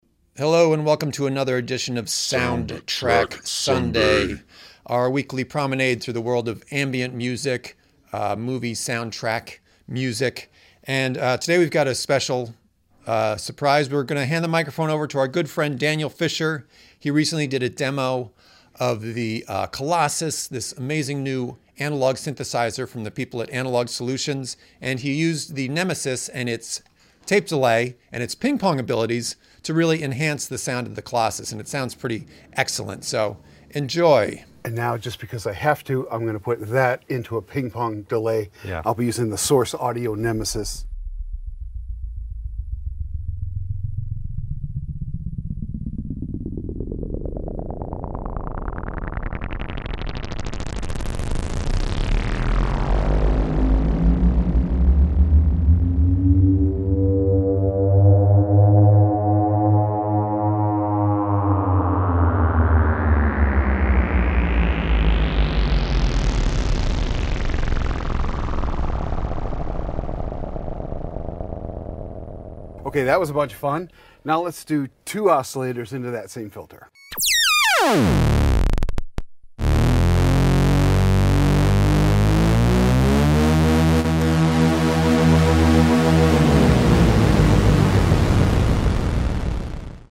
Rocking some Nemesis Delay with the Colossus analog synth from Analogue Solutions.